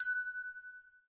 icechime.ogg